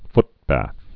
(ftbăth, -bäth)